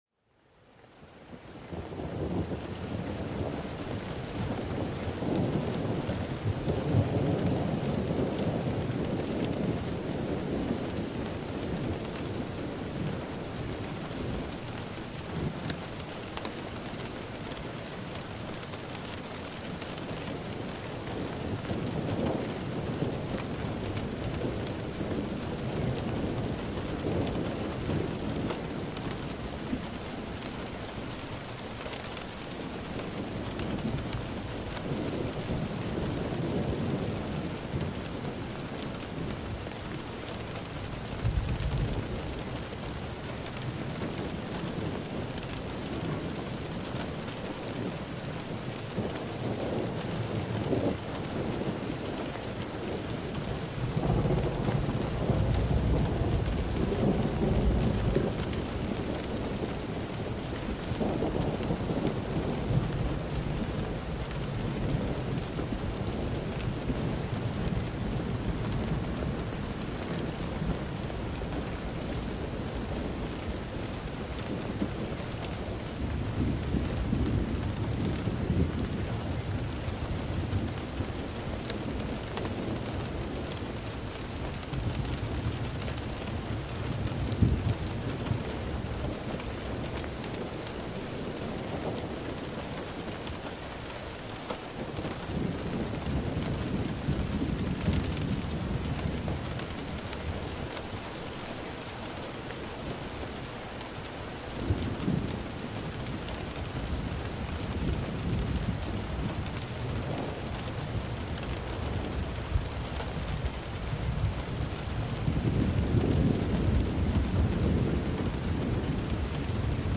RAINYAURA - ASMR Archive
rainingsound.m4a